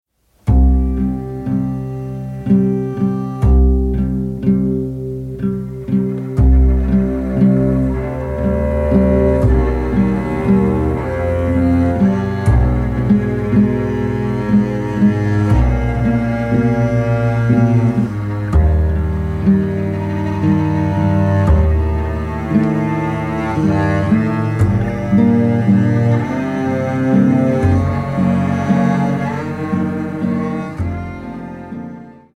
Atmospheric and beautiful, virtuosic yet loose and heartful